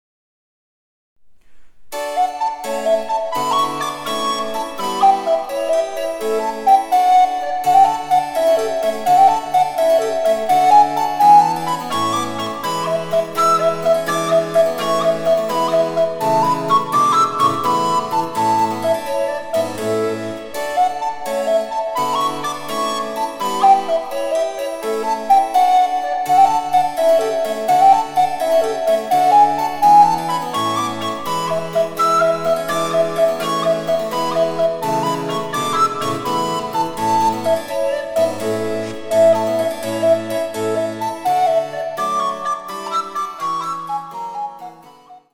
第５楽章は８分の６拍子で、快活なジークです。
■リコーダーによる演奏